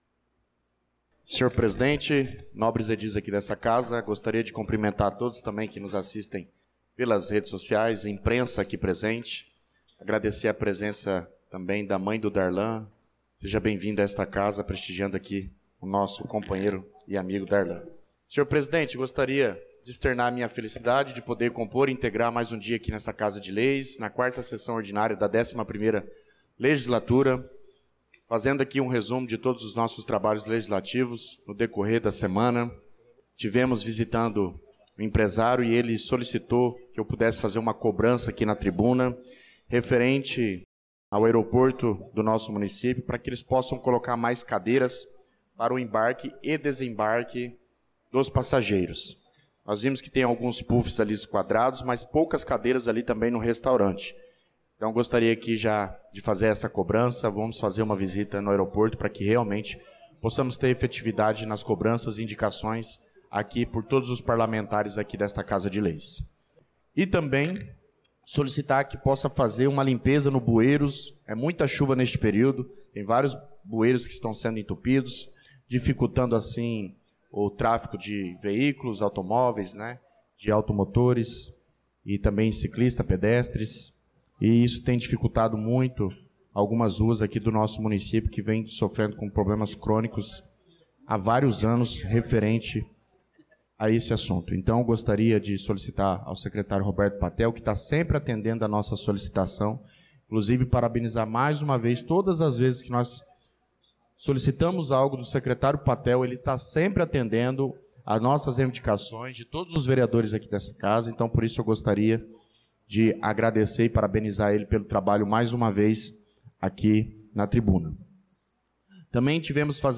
Pronunciamento do vereador Douglas Teixeira na Sessão Ordinária do dia 25/02/2025